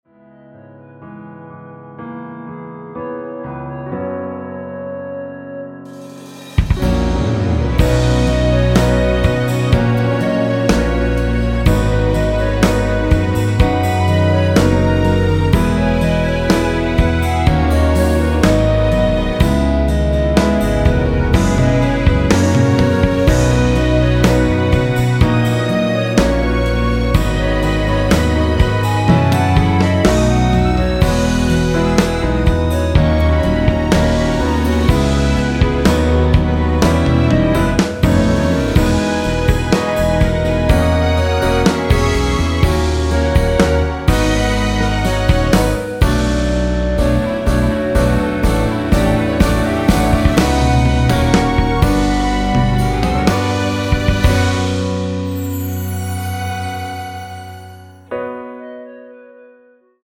원키에서(-2)내린 멜로디 포함된 1절후 후렴으로 진행 되는 MR입니다.(본문 가사 확인)
◈ 곡명 옆 (-1)은 반음 내림, (+1)은 반음 올림 입니다.
멜로디 MR이라고 합니다.
앞부분30초, 뒷부분30초씩 편집해서 올려 드리고 있습니다.